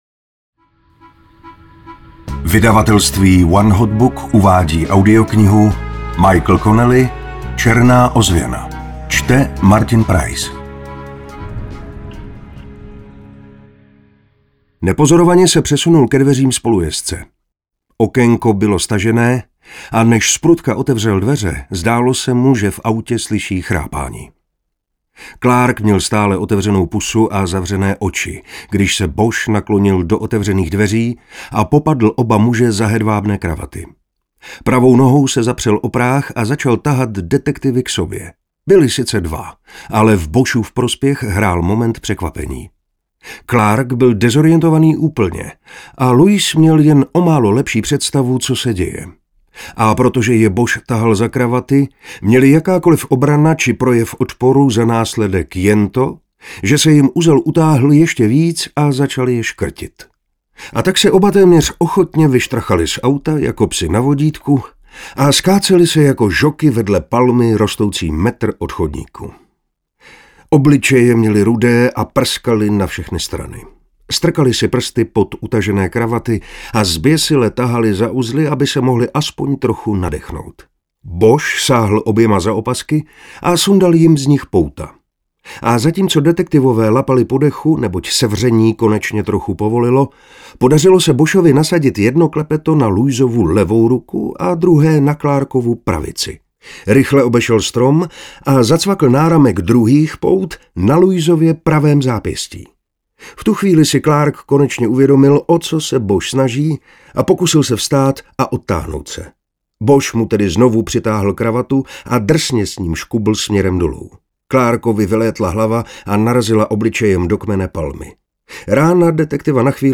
Černá ozvěna audiokniha
Ukázka z knihy
• InterpretMartin Preiss